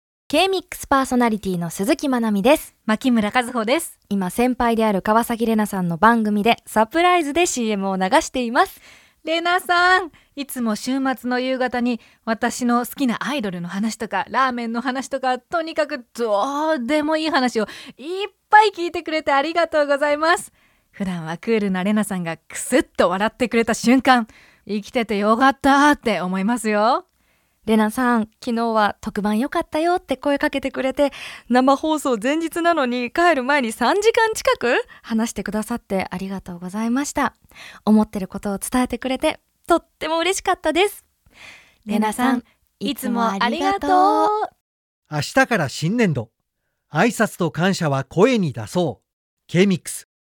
ナレーション